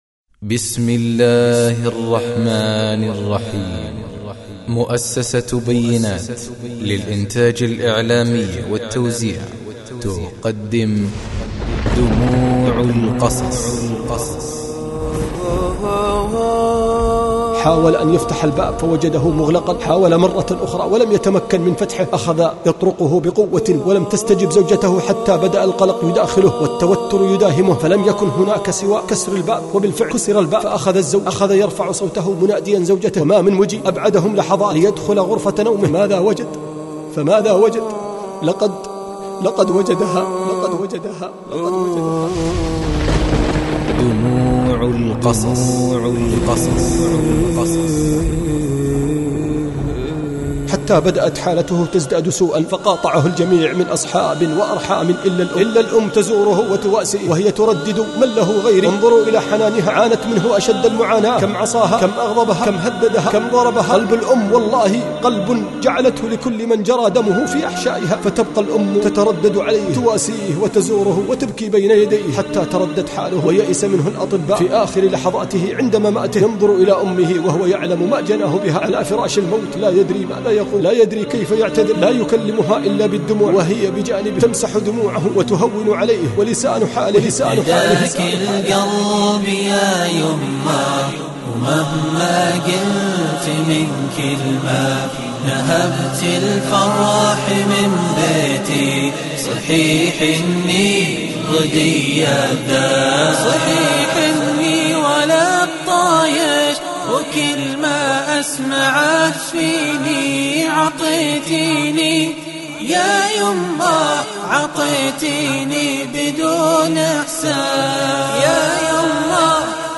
تقديم
إنشاد
الهندسة الصوتية والإخراج